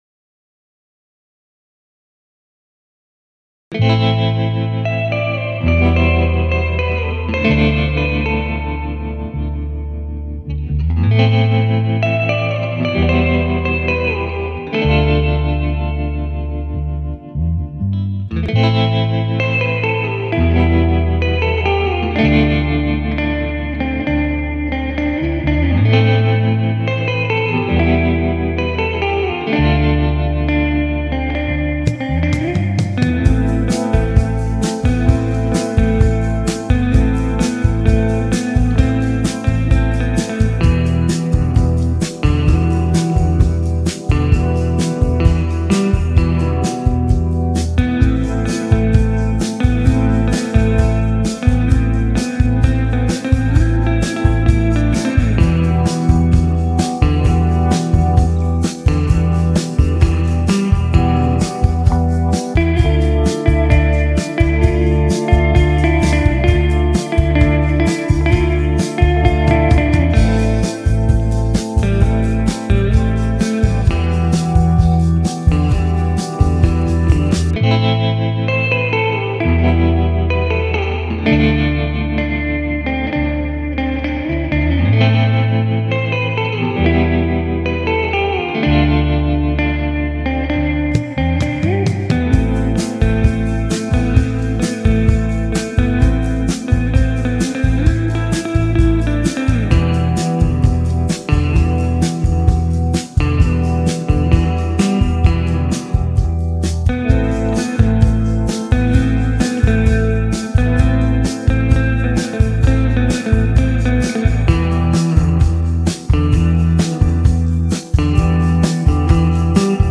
I did all the guitar parts using Mixcraft 9 and My Kemper Rack unit
Mystic Teal Jazzmaster - Doing all the guitar work